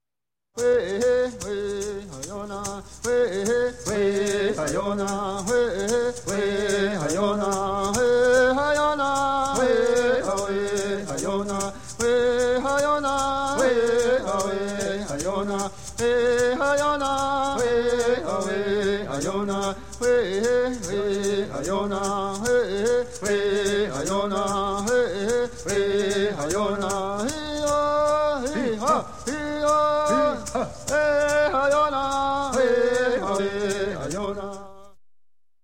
Запись индейской песни с маракасами